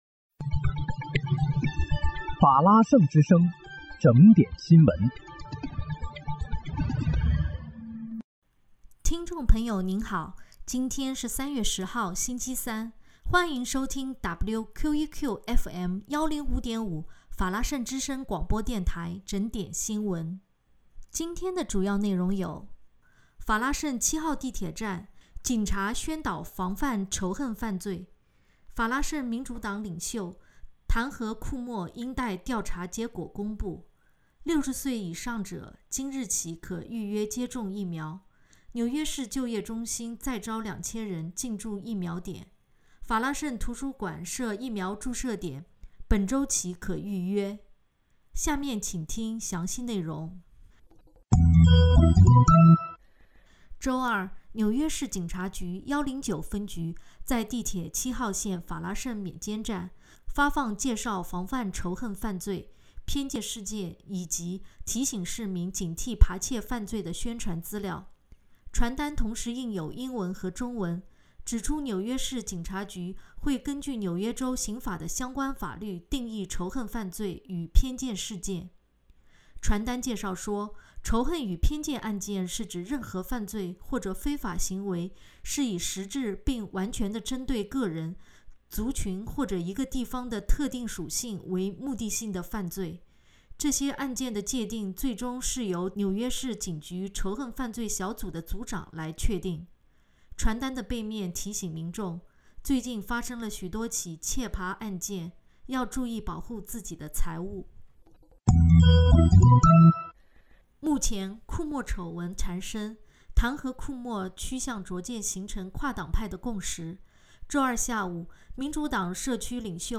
3月10日（星期三）纽约整点新闻